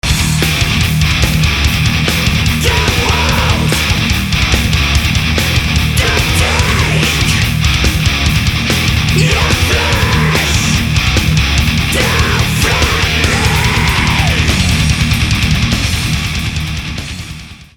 *Genre: Melodic Thrash/Death Metal